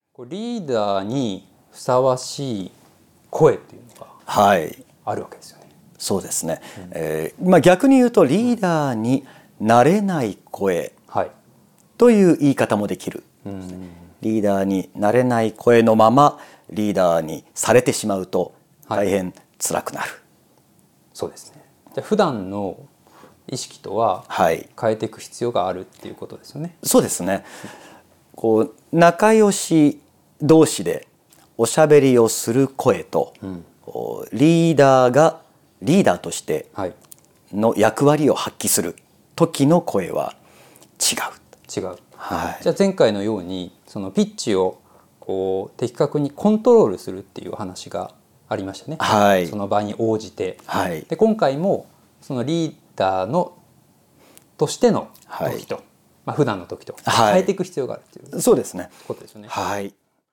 この音声講座は上記の「カートに入れる」ボタンから個別購入することで聴くことができます。